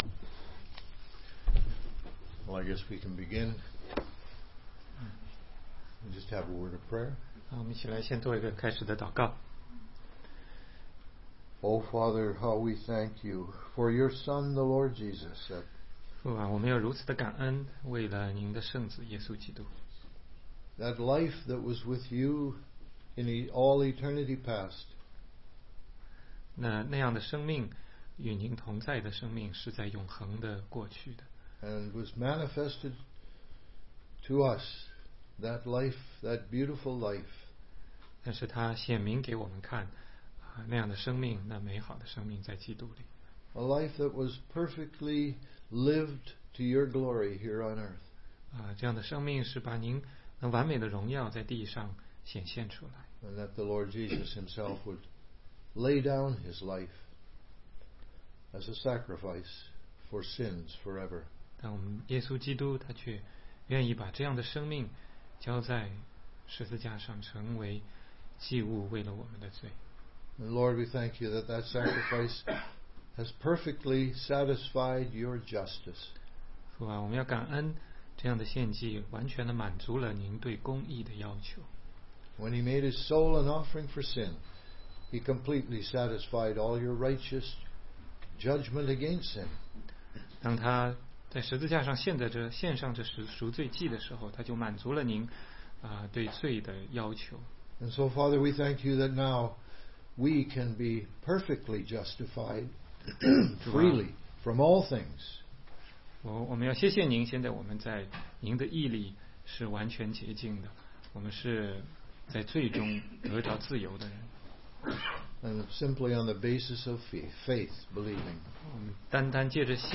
16街讲道录音 - 约翰福音6章66-71节